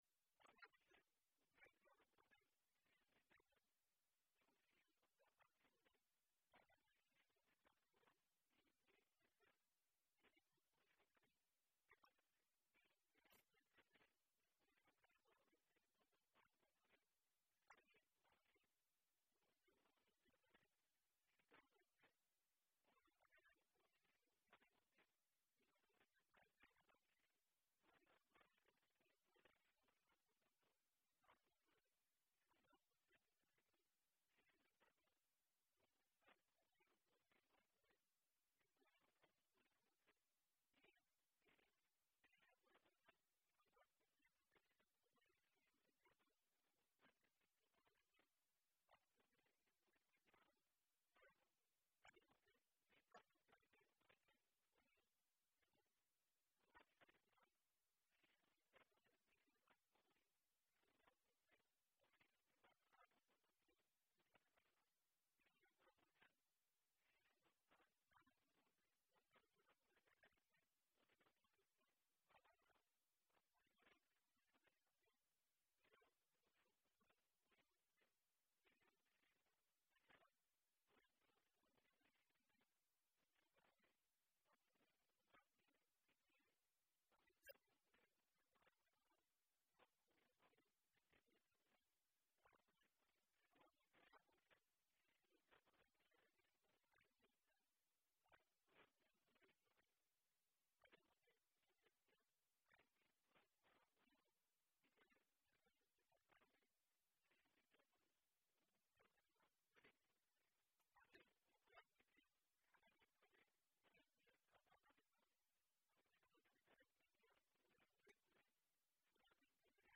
রিপোর্ট